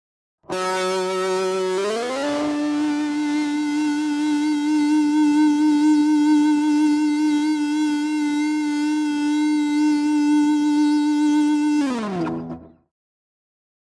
Rock Guitar Distorted FX 7 - Long Sustain Tone 4